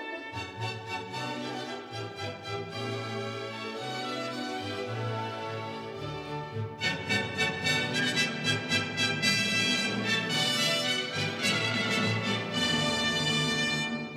These are arranged in degrading quality.